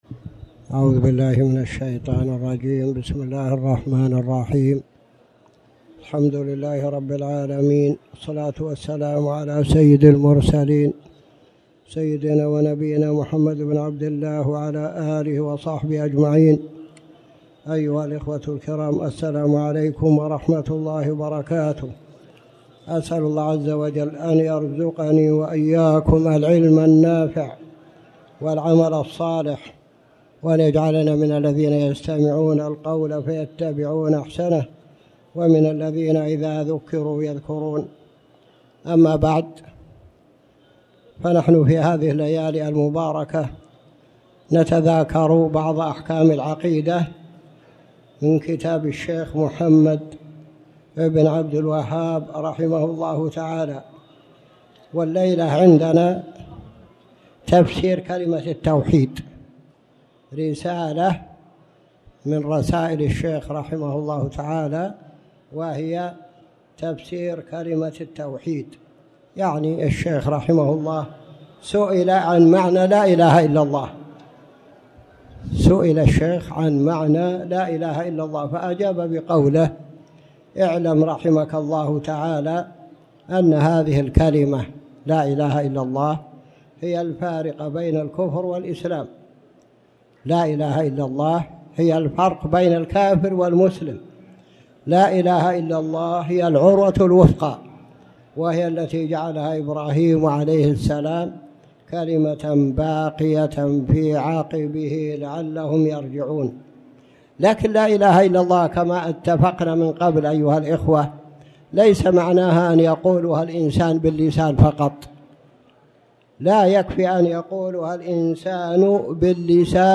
تاريخ النشر ١٨ جمادى الآخرة ١٤٣٩ هـ المكان: المسجد الحرام الشيخ